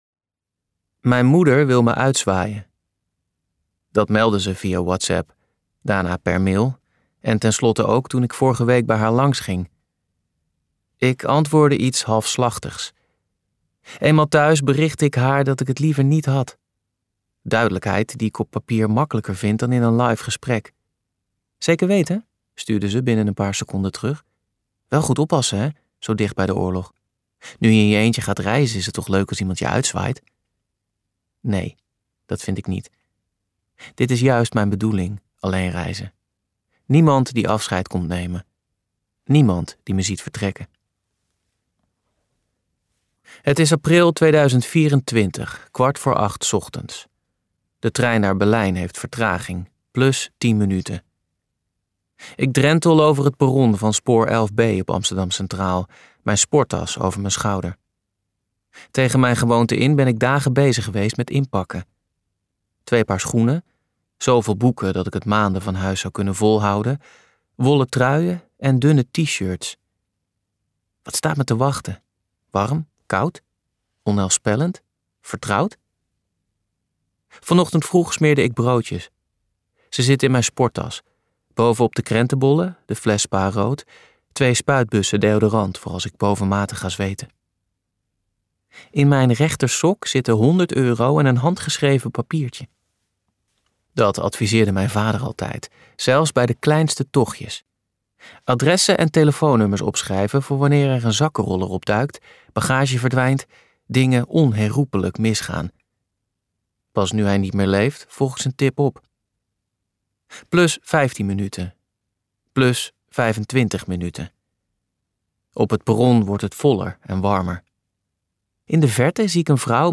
Het baltisch station luisterboek | Ambo|Anthos Uitgevers